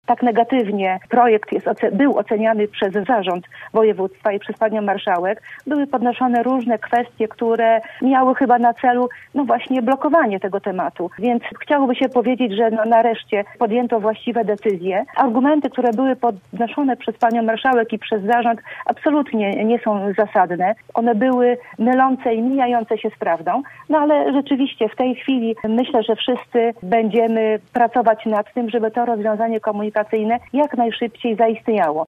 Małgorzata Gośniowska – Kola była gościem Rozmowy po 9.